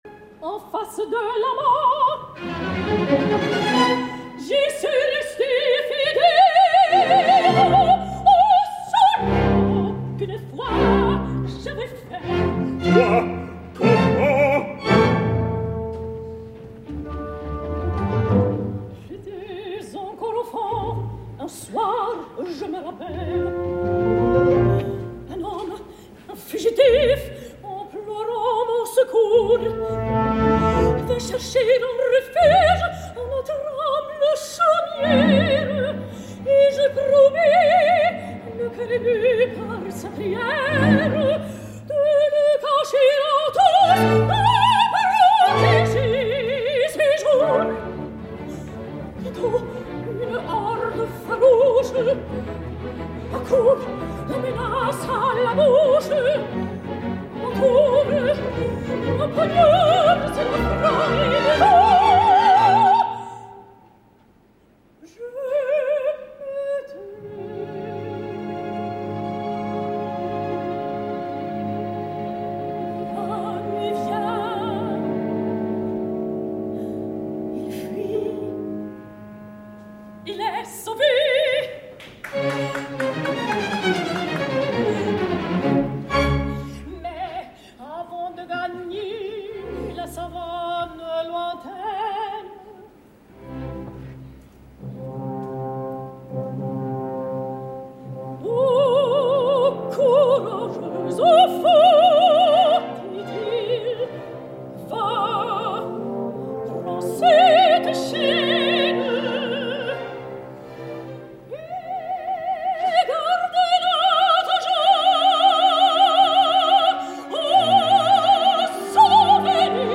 Tornen Les pêcheurs de perles a IFL, ara arran d’una producció efectuada al Theater an der Wien de la capital austríaca, sota la direcció musical de Jean-Christophe Spinosi, allunyat del seu repertori habitual. i amb un repartiment encapçalat per la deliciosa Diana Damrau en el rol de Leila, al costat del tenor rus Dmitry Korchak, del baríton nord-americà Nathan Gunn (proper Danilo a la Viuda alege al MET) i del baix francès Nicolas Testé, tots tres més que correctes però per sota de la soprano alemanya, que un cop més convenç per la intensitat i la dolçor del seu cant.